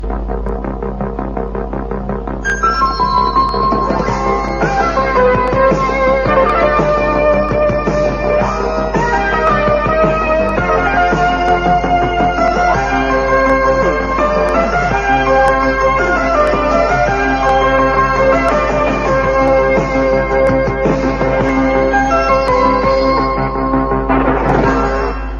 Indicatiu instrumental de la cadena